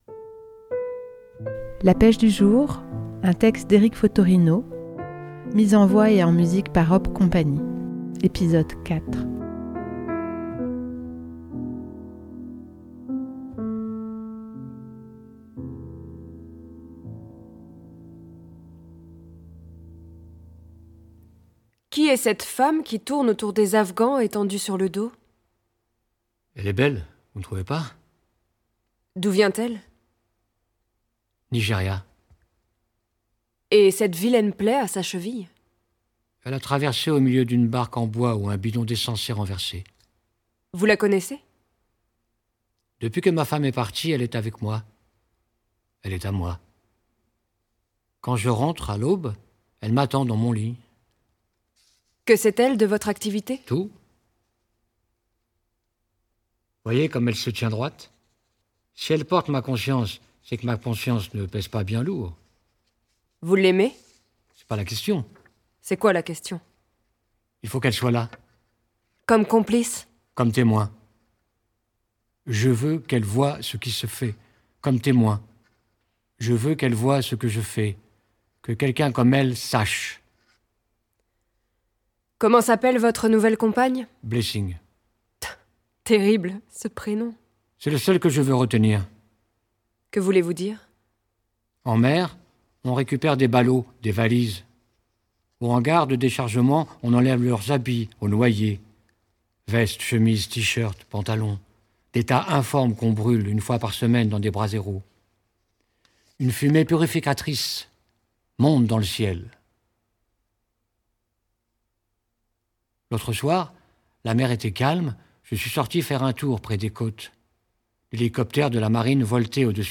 Hop Cie met en scène et en musique le texte coup de poing d'Eric Fottorino "La pêche du jour".